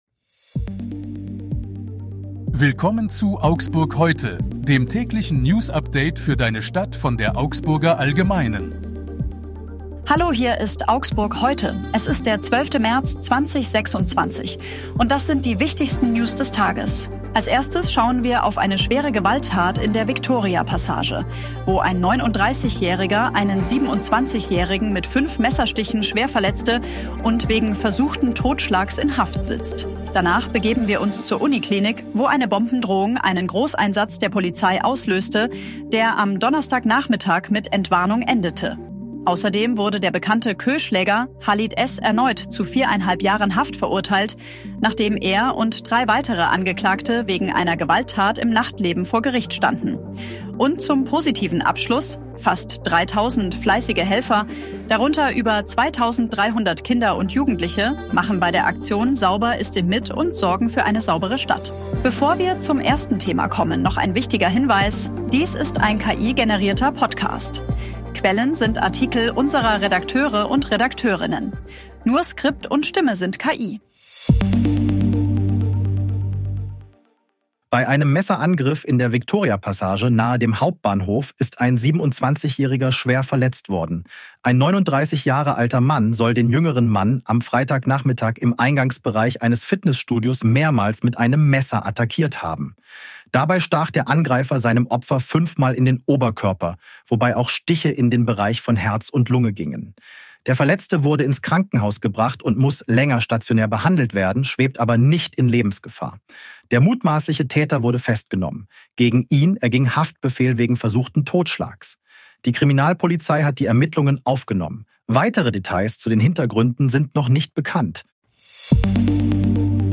Skript und Stimme sind KI.